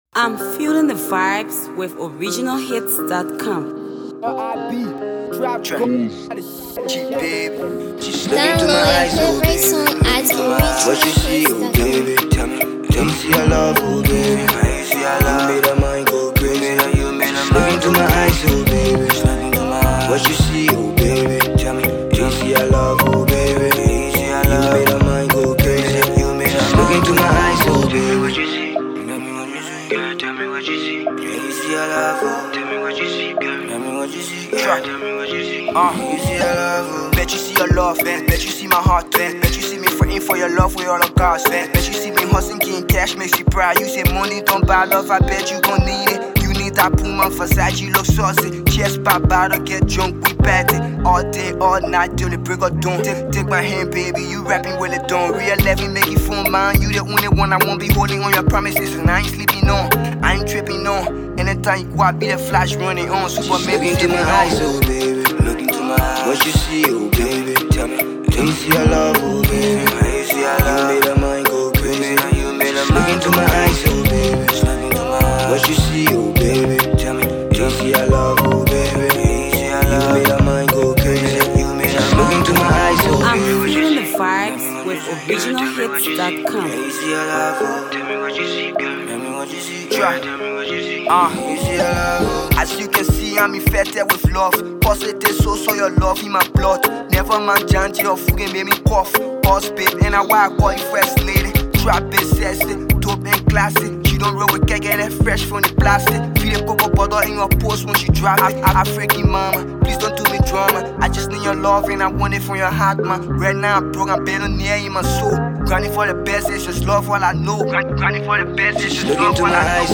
Liberian uprising trap artist